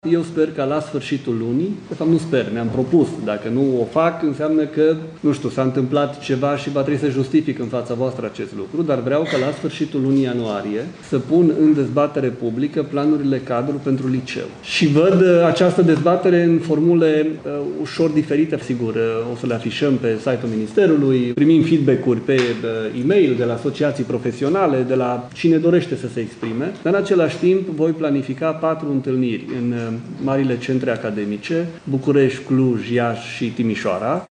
Ministrul Educației, Daniel David: Vreau ca la sfârșitul lunii ianuarie să pun în dezbatere publică planurile-cadru pentru liceu